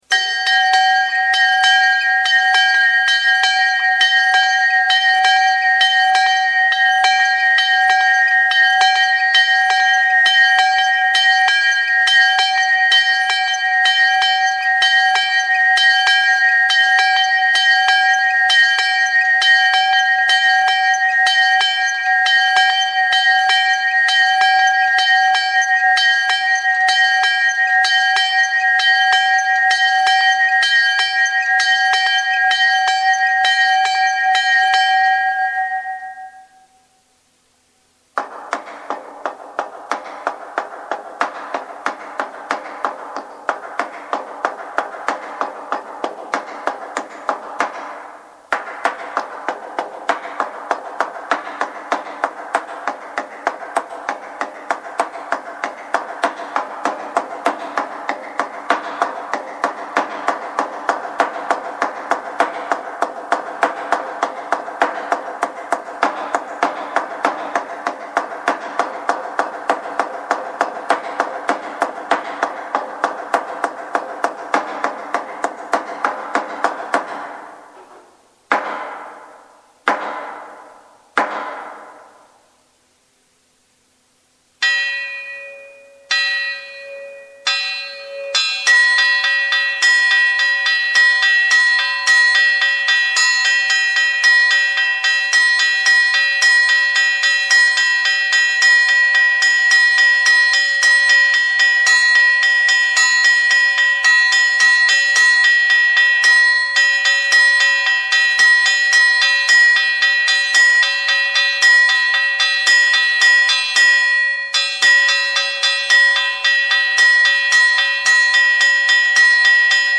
Campane.mp3